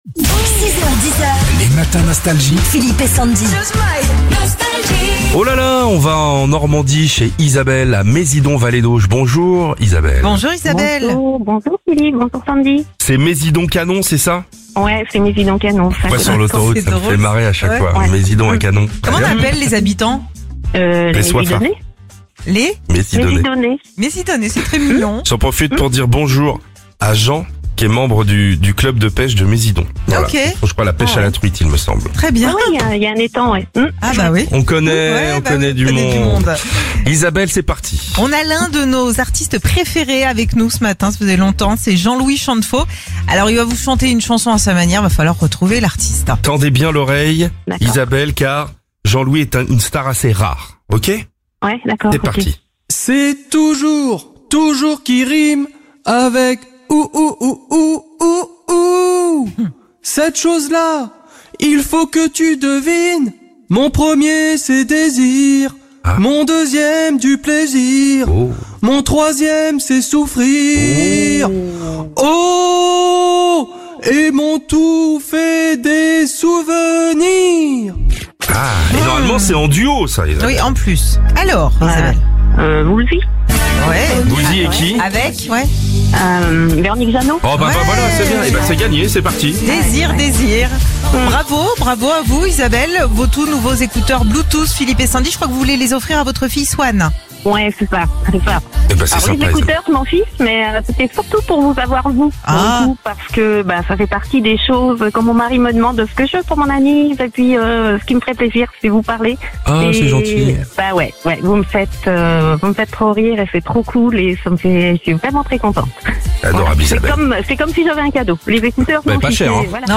Il va vous chanter à sa manière un tube. Vous le retrouvez et c’est gagné !